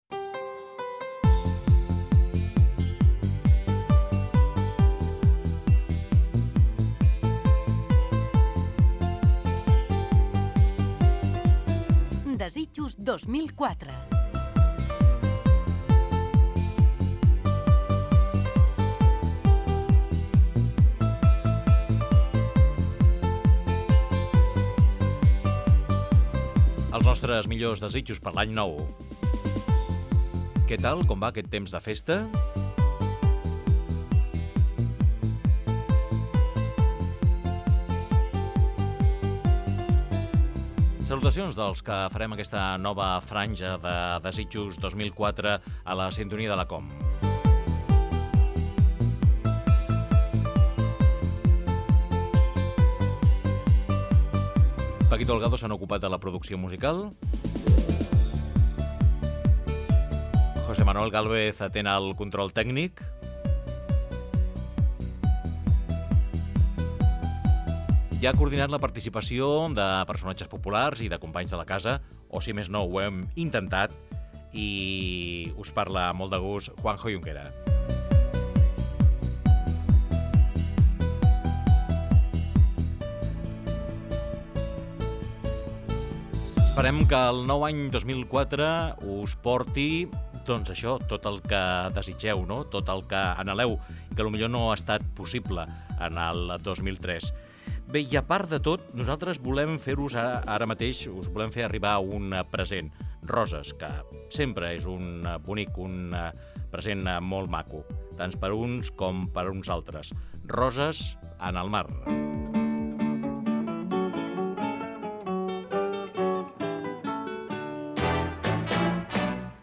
Inici del programa amb els nom de l'equip. Tema musical.
Entreteniment
FM